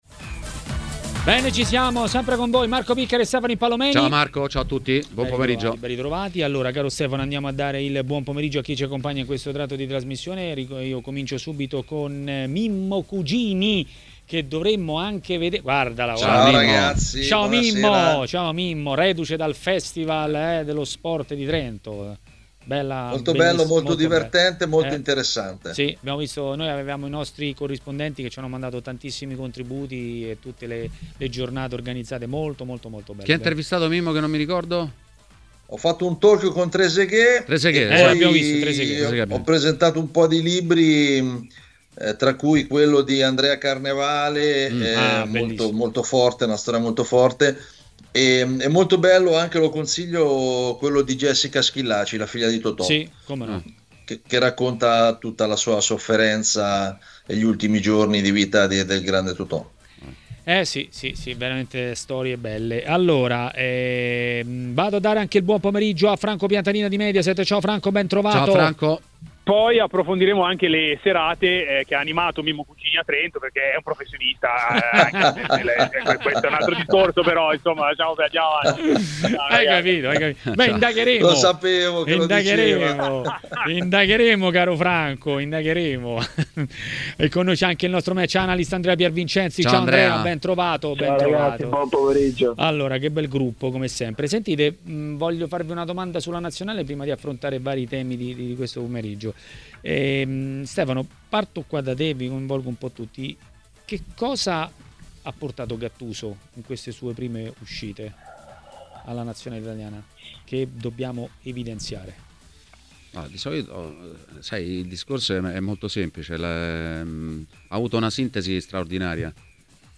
Ospite di TMW Radio, durante Maracanà